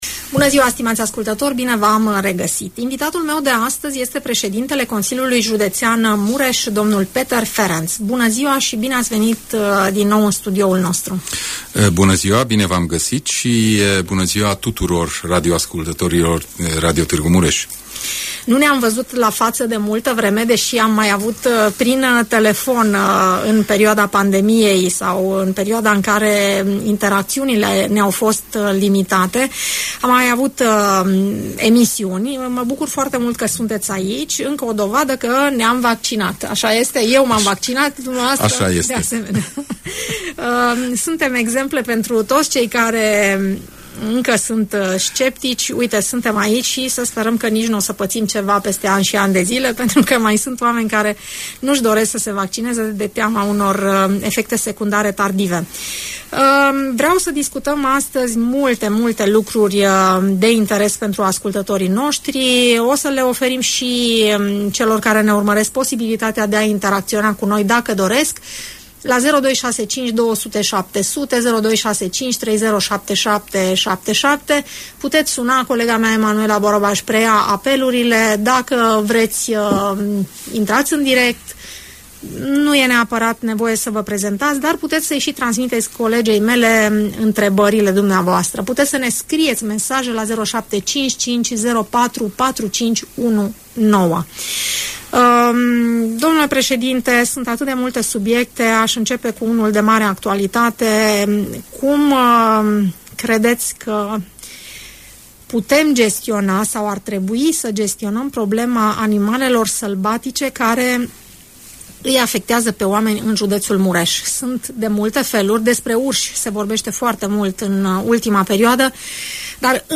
Peter Ferenc, președintele Consiliului Județean Mureș, explică în emisiunea „Părerea ta”